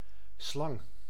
Ääntäminen
IPA: [slɑŋ]